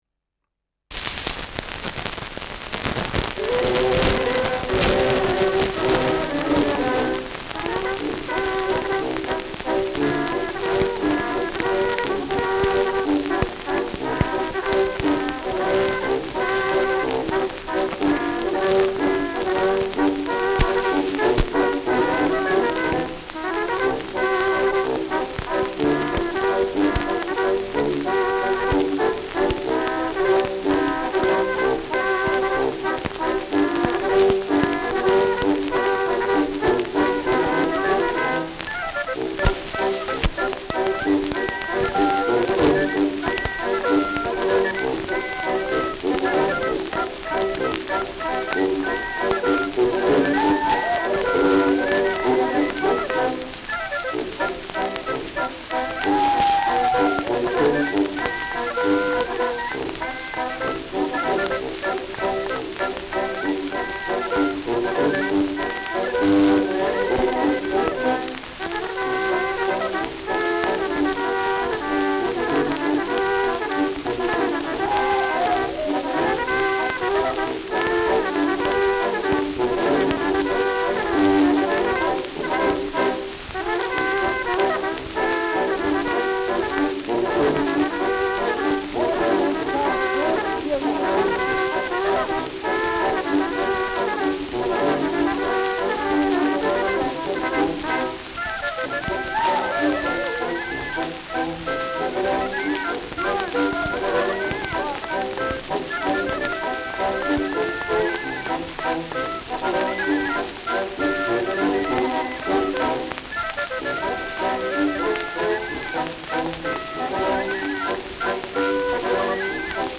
Schellackplatte
starkes Rauschen : durchgängig dumpfes Knacken : leichtes Leiern : starkes Knistern
Truderinger, Salzburg (Interpretation)
Mit Juchzern, Zwischenrufen und Klatschen.